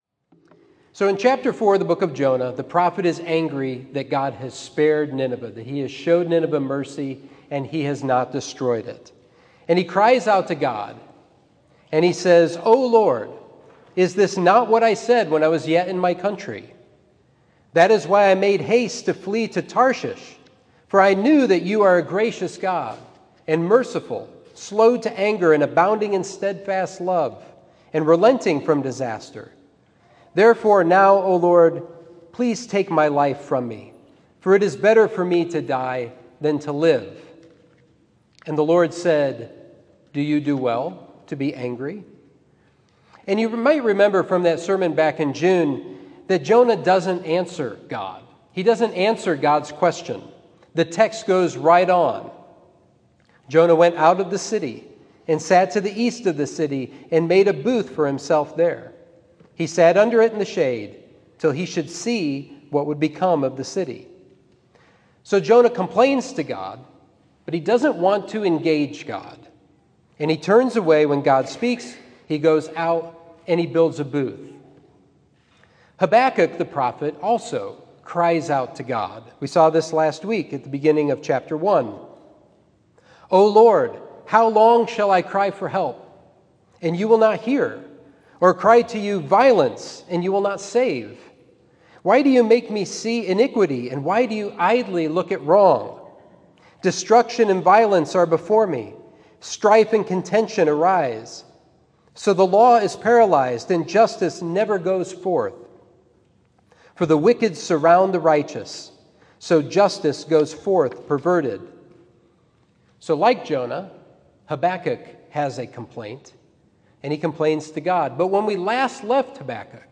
Sermon 8/22: Habakkuk: Faithfully Living by God's Faithfulness